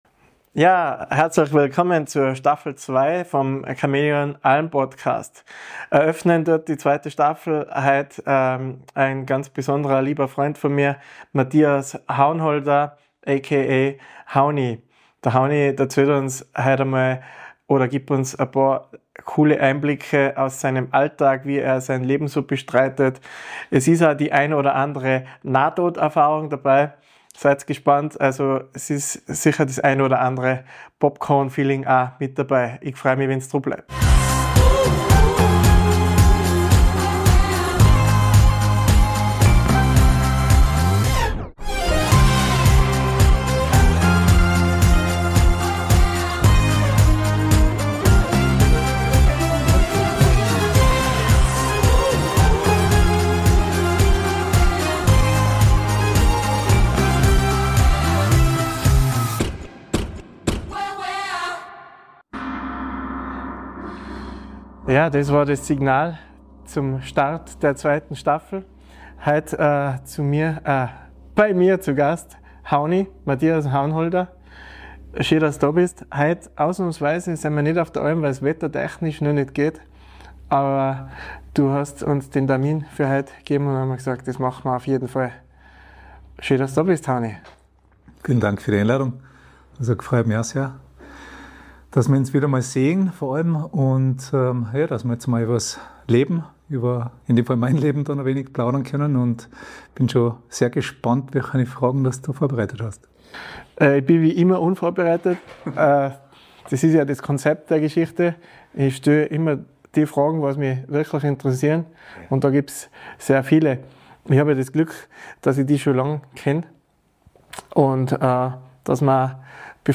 Ein ehrliches Gespräch über Risiko, Mindset und den Weg zum Erfolg.